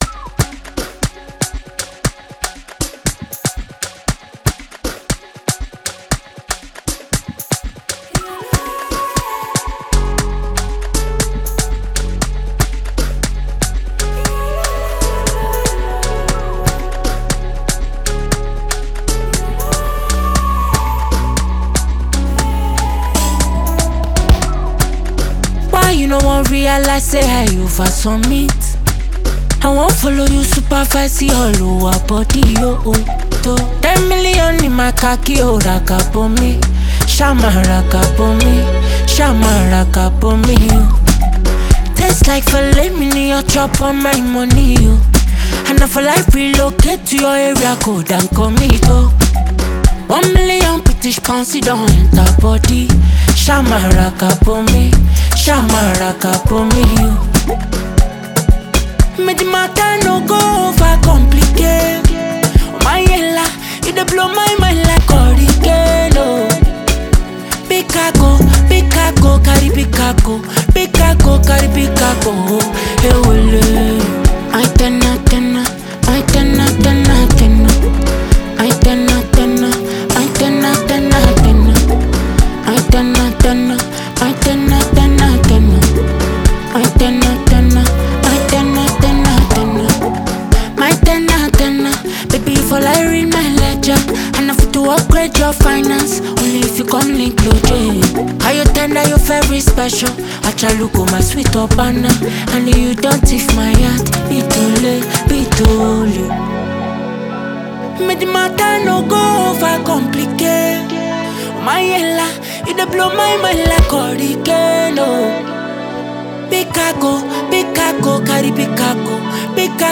The song delivers a fresh melodic experience
smooth vocal delivery